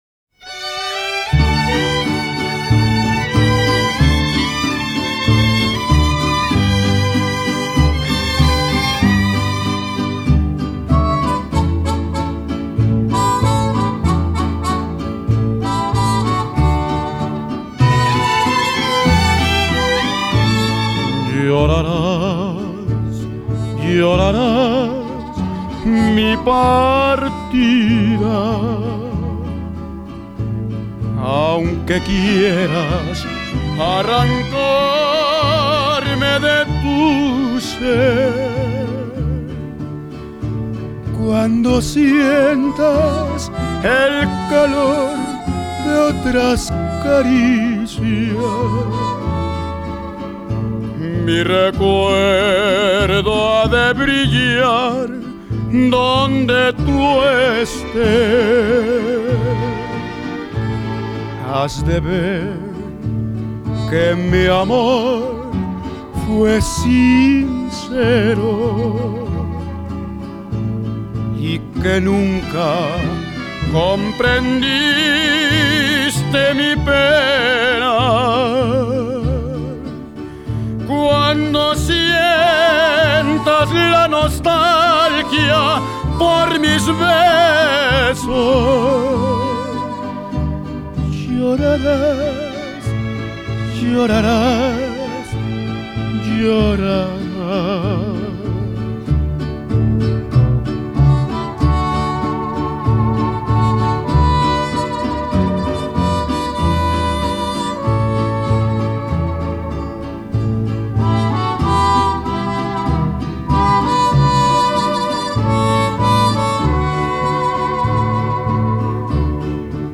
Llora la canción.